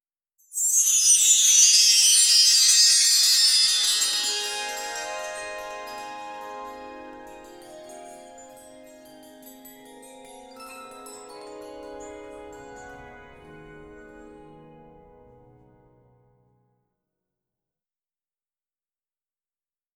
Peaceful, divine, and awe-inspiring atmosphere." 0:20 Created May 10, 2025 1:41 AM
angelic-sound-with-soft-c-kw6zez3w.wav